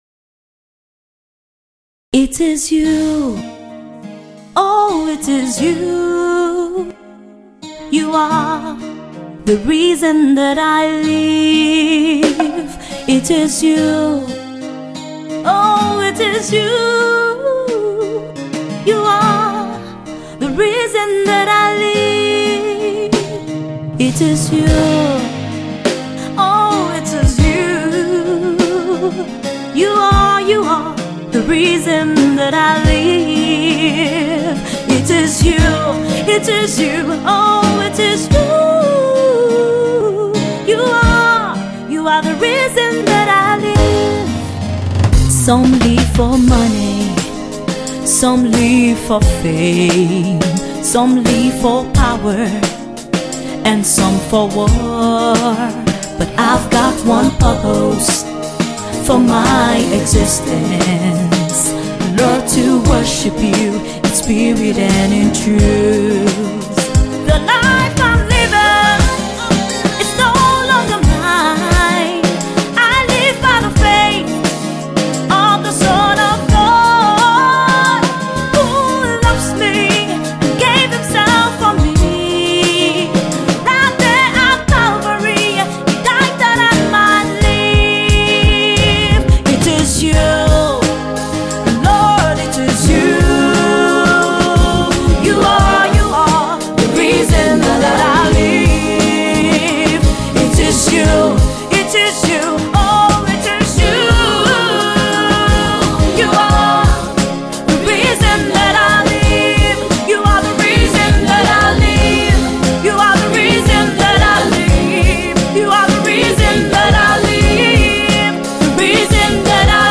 a powerful rock piece
on the Rock Guiter.